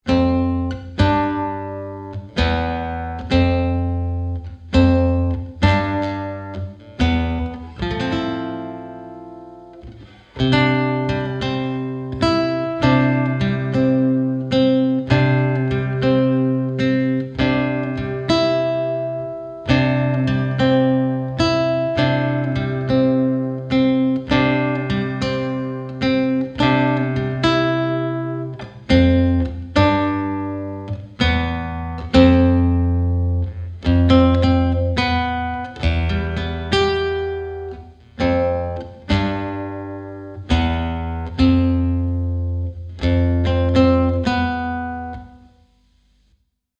Chitarra sola 52